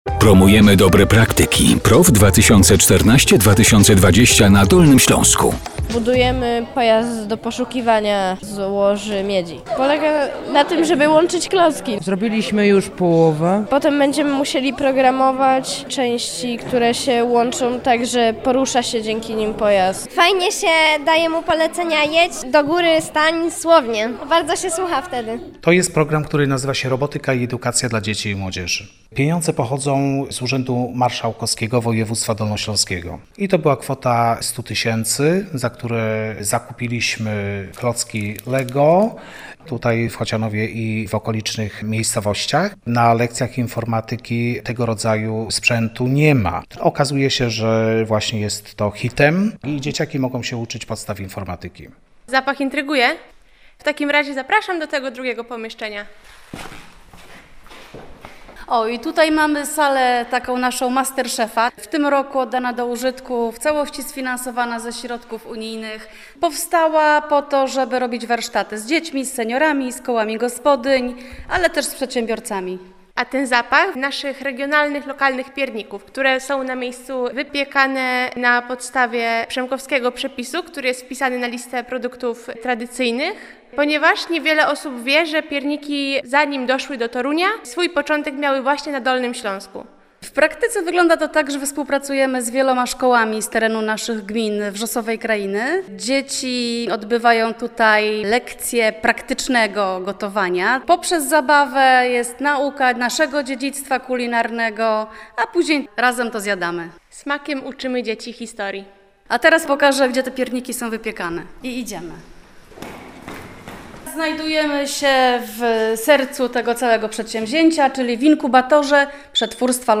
Z radością informujemy, że Wrzosowa Kraina miała przyjemność wziąć udział w audycji na antenie Radio Wrocław! 🎙 Emisja odbyła się 19 listopada 2024 roku o godzinie 12:15.